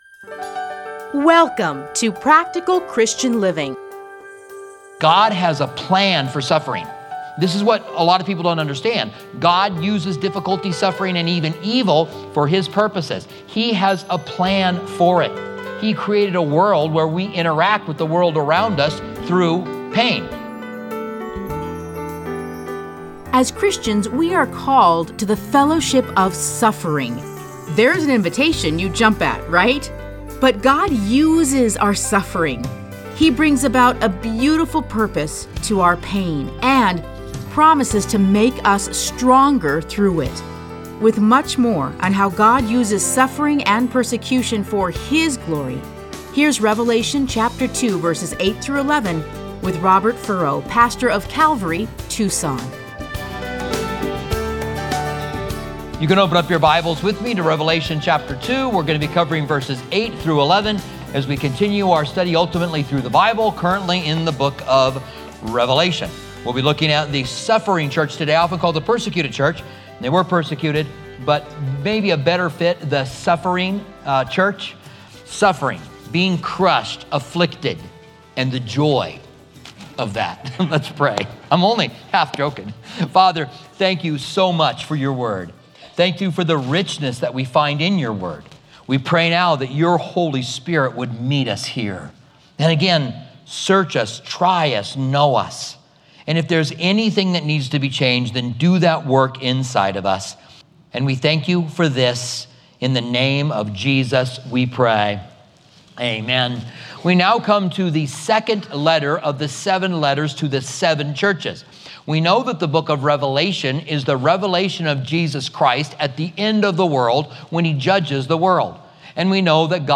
Listen to a teaching from Revelation 2:8-11.